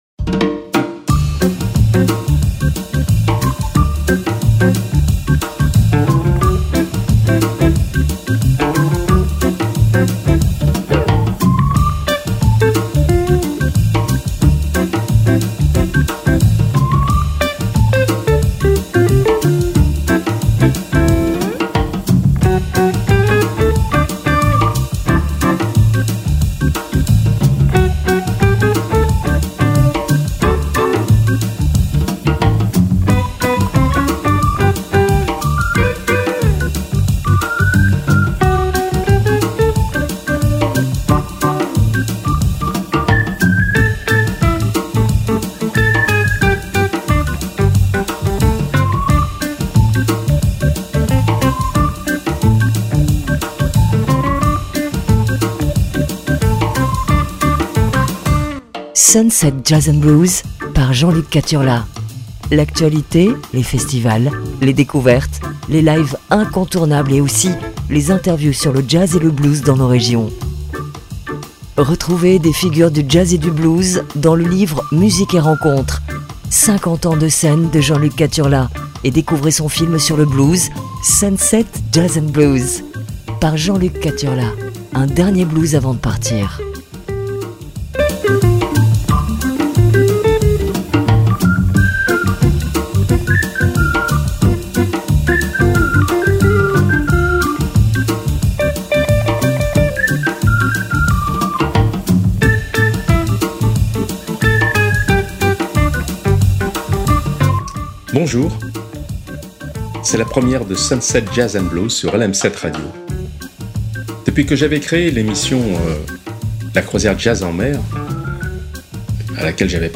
Les Vendredis Soirs sont très Jazzy